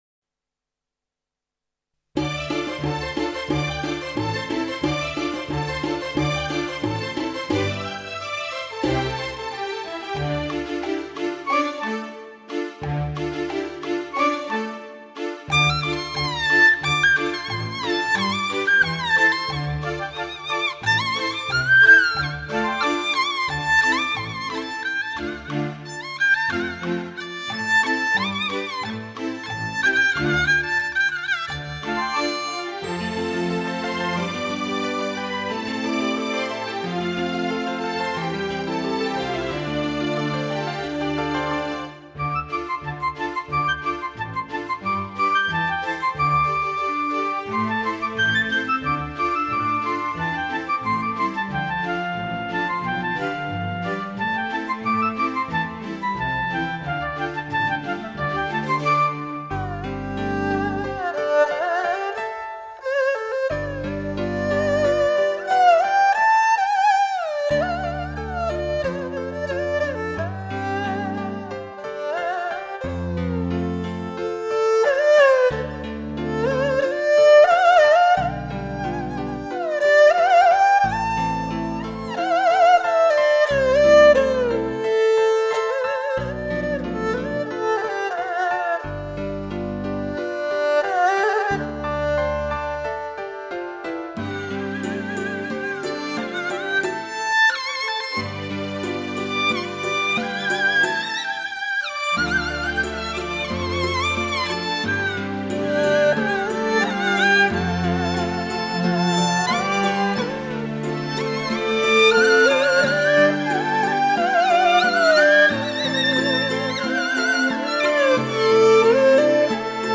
乐队由中国的民族乐器为主奏和领奏并辅以西洋管弦乐队及适量的电声乐器。
最新数码系统录制，发烧品质，优美感人。